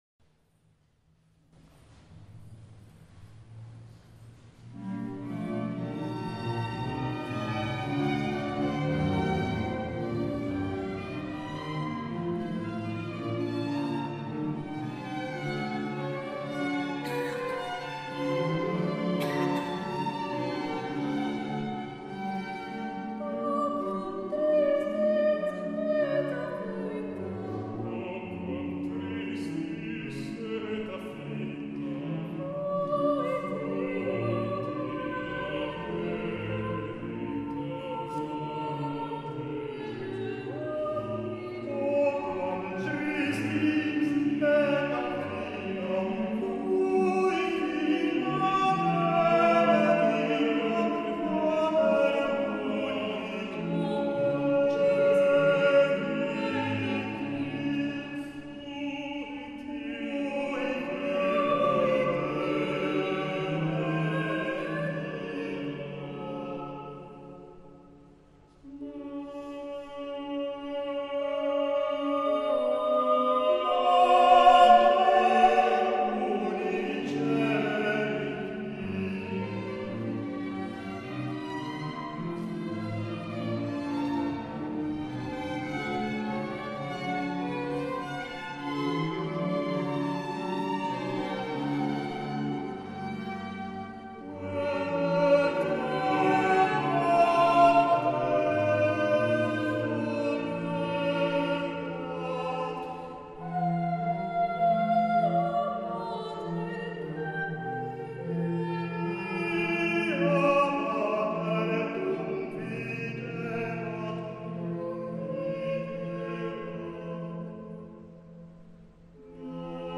Soloist Music